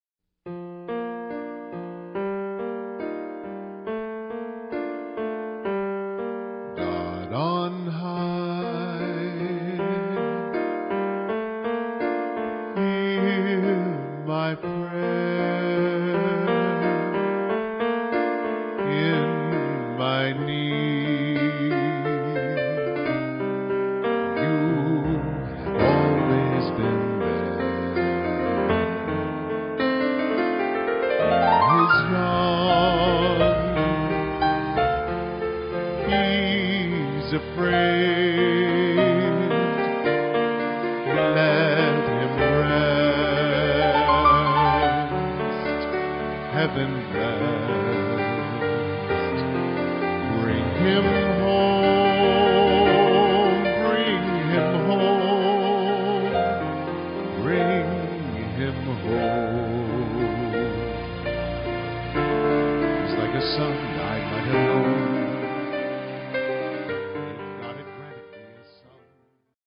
Showtune